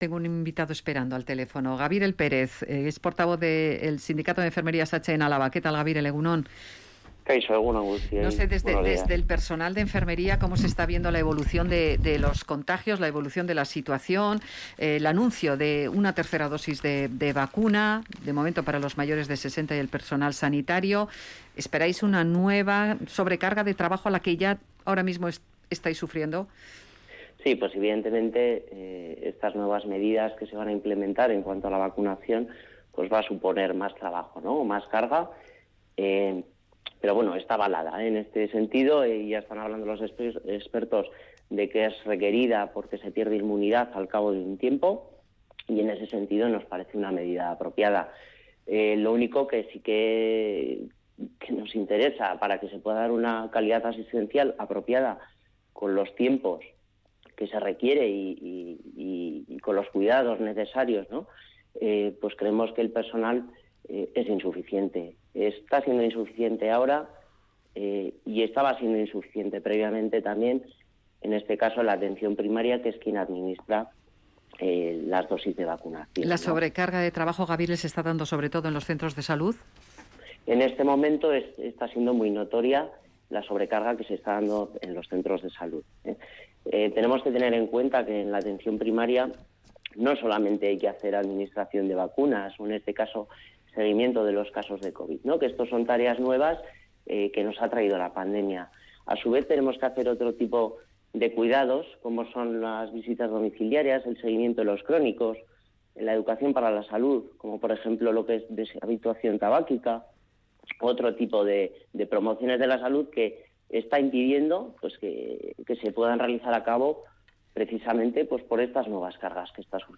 En Radio Vitoria Gaur hemos hablado con el sindicato de enfermería SATSE. Denuncian que el refuerzo de personal ha llegado tan solo a 7 centros de salud alaveses.